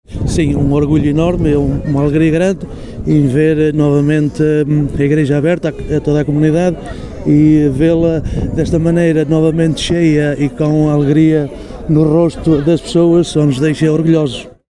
Fernando Guedes, Presidente da Junta de Freguesia de Vila Cova à Coelheira, disse que é com grande orgulho que vê esta inauguração, “ver a alegria no rosto das pessoas só nos deixa orgulhosos…”.